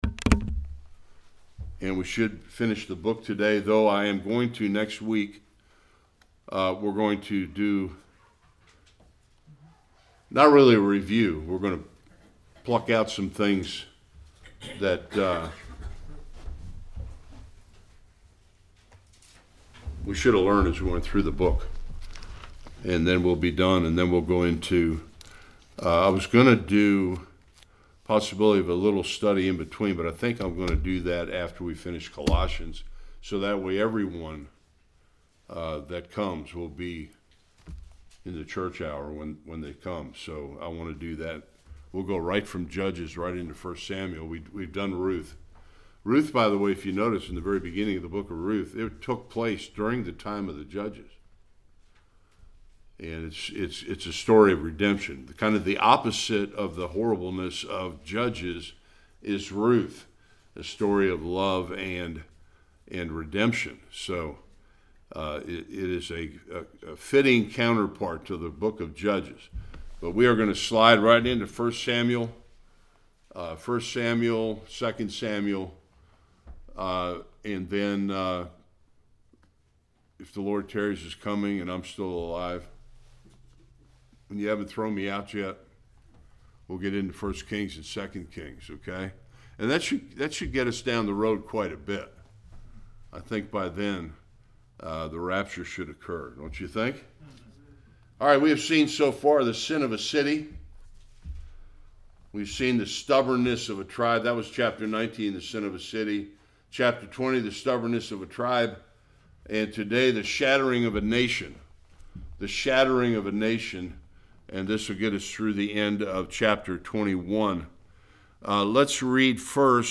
1-21 Service Type: Sunday School The tribe of Benjamin was in danger of being wiped out.